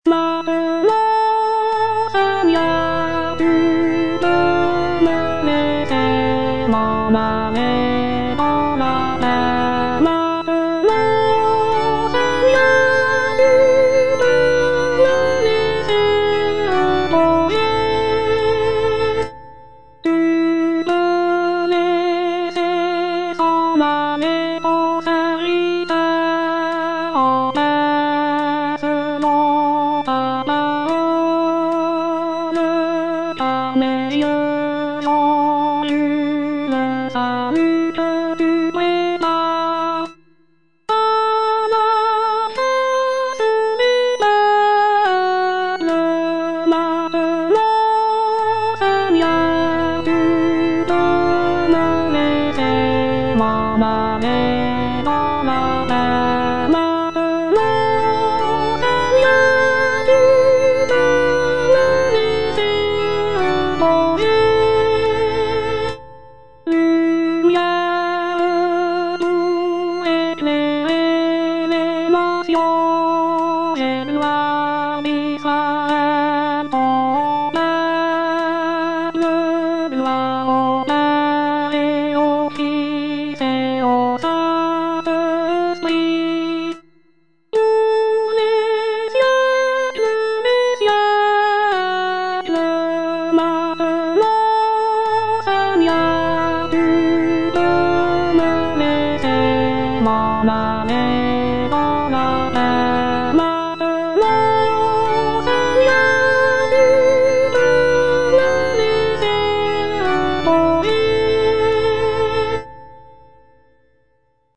Soprano (Voice with metronome)